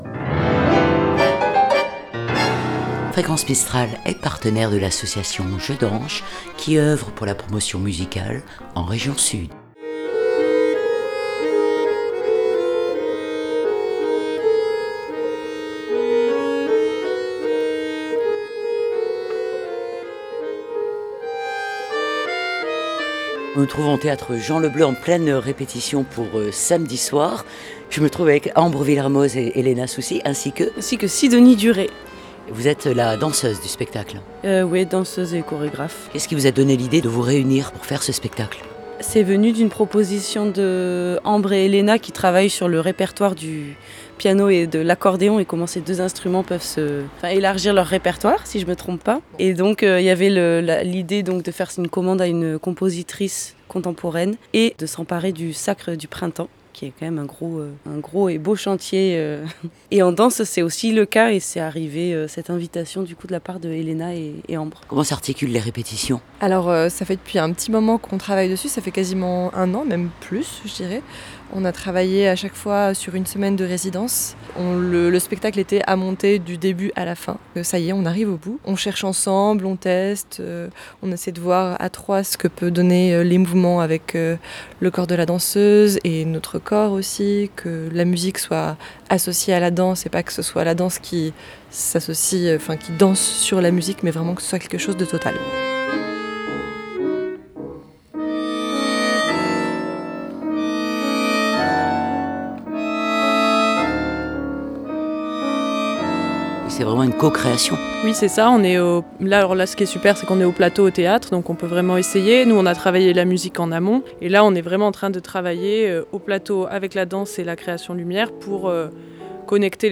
Jeux Anches-Ballet de Poche répétition- théâtre Jean Le Bleu.wav (62.06 Mo)
La timbale est donc dansée, les vents soufflent avec l’accordéon, les cordes résonnent dans le piano, et les cartes sont rebattues à chaque instant.